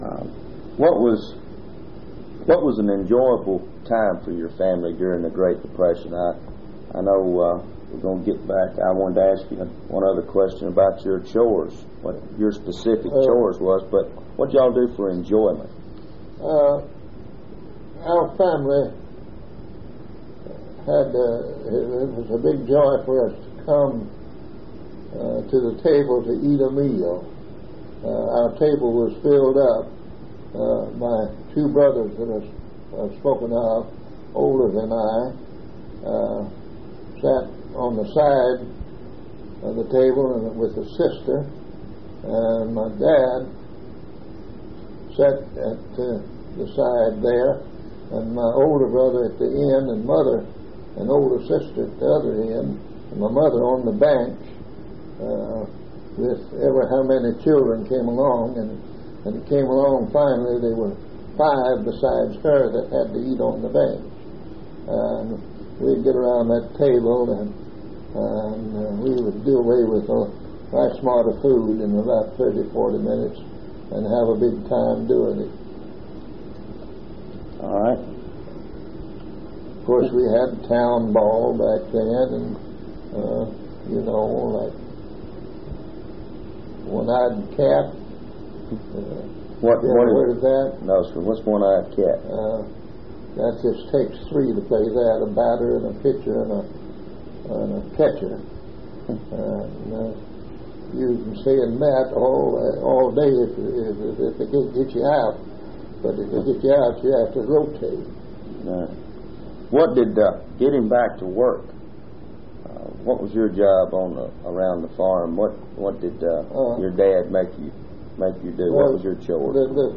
Oral History Collection